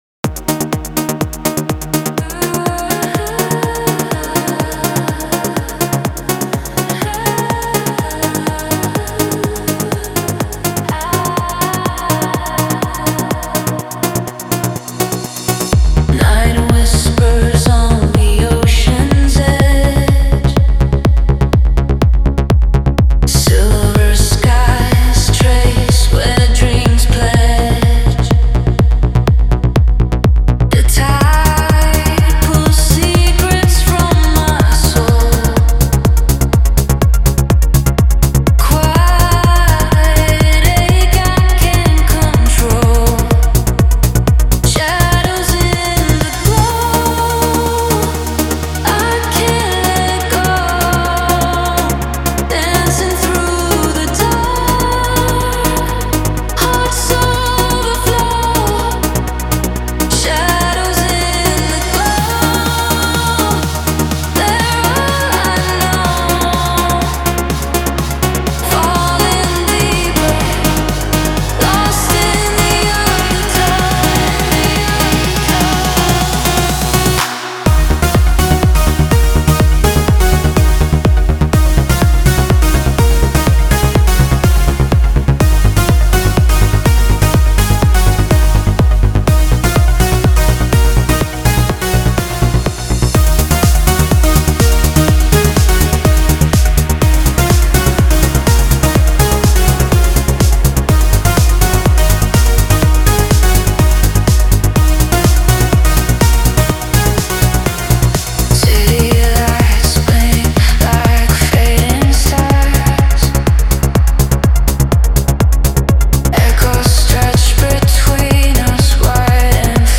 Диско